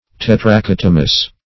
Search Result for " tetrachotomous" : The Collaborative International Dictionary of English v.0.48: Tetrachotomous \Tet`ra*chot"o*mous\, a. [Gr. te`tracha in four parts + te`mnein to cut.]
tetrachotomous.mp3